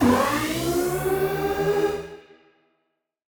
Index of /musicradar/future-rave-samples/Siren-Horn Type Hits/Ramp Up
FR_SirHornA[up]-E.wav